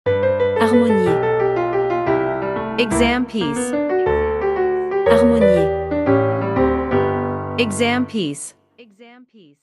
Professional-level Piano Exam Practice Materials.
• Vocal metronome and beats counting